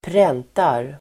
Uttal: [²pr'en:tar]